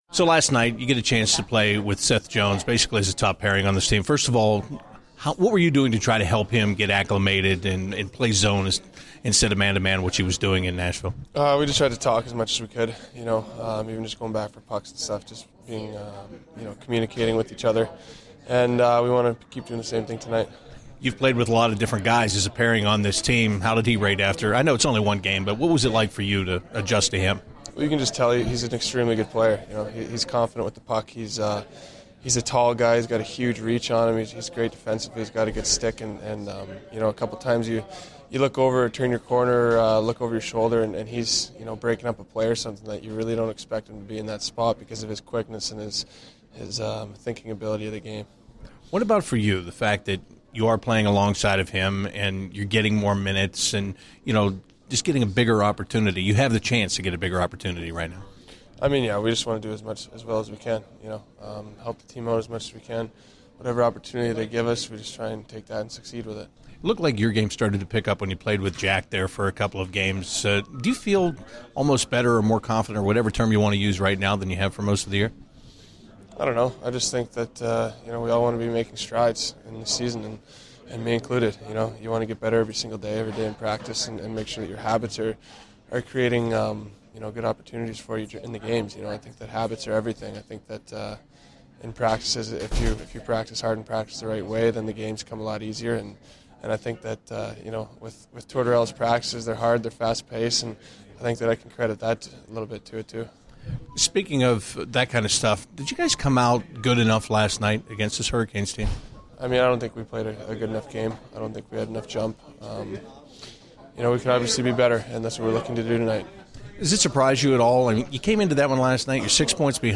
CBJ Interviews / Ryan Murray Pre-Game 01/09/16
An episode by CBJ Interviews